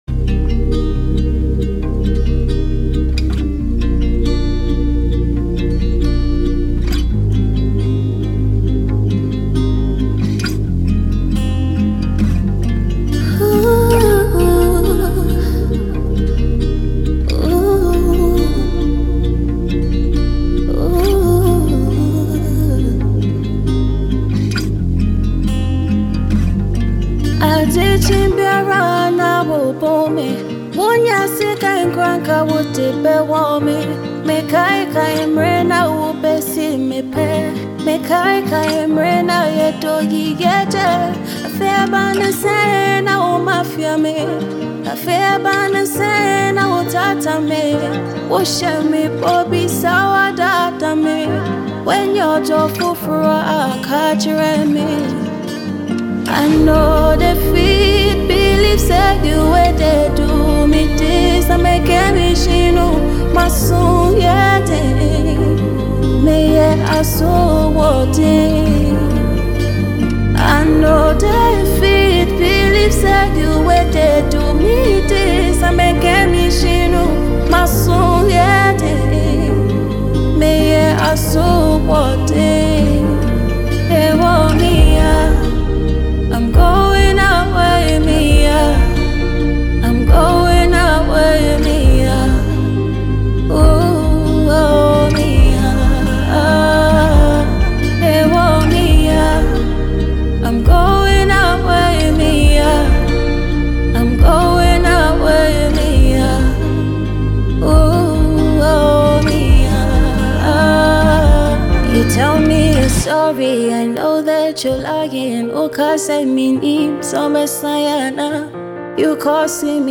Ghanaian AfroSoul and R&B singer